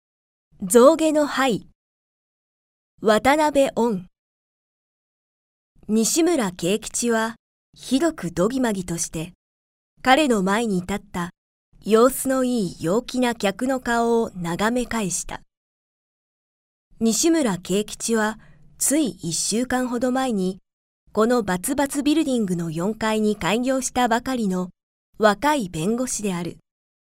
朗読ＣＤ　朗読街道129「象牙の牌」渡辺温
朗読街道は作品の価値を損なうことなくノーカットで朗読しています。